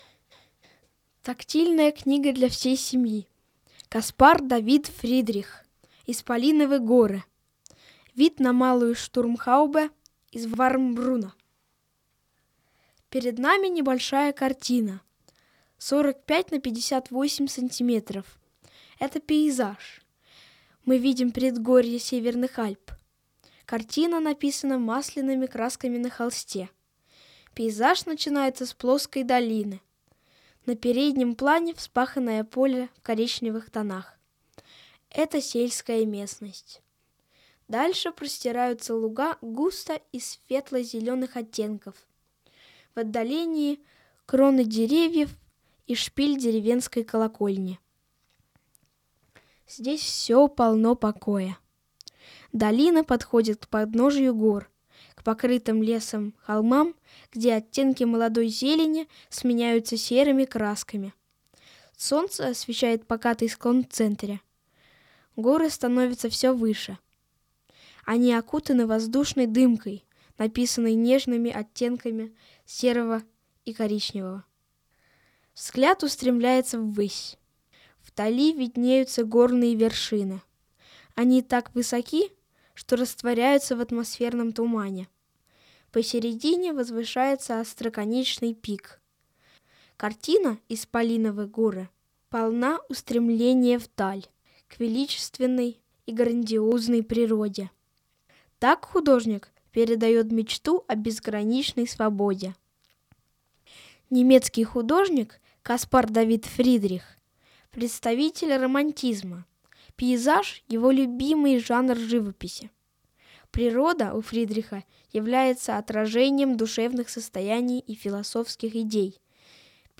Тифлоподкаст к тактильной книге для всей семьи о картине немецкого художника Каспара Давида Фридриха «Исполиновы горы». Рекомендуем использовать аудио как дополнение при знакомстве с изданием.